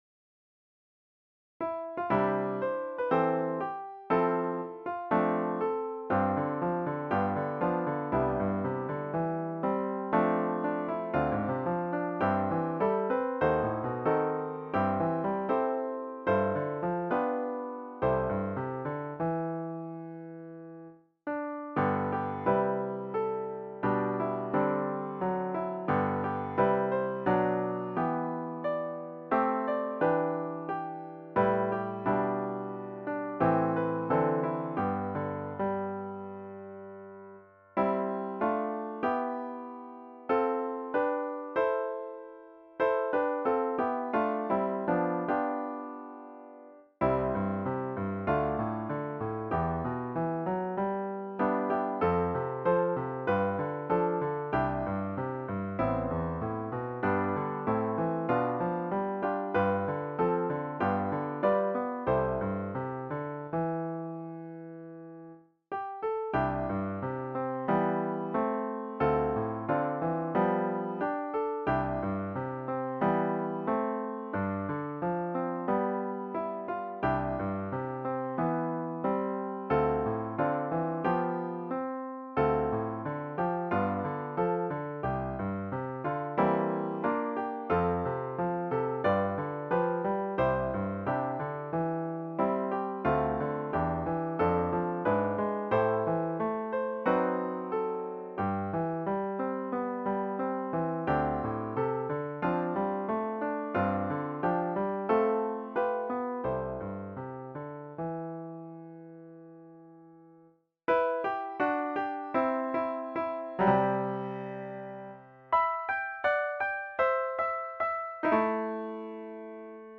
piano medley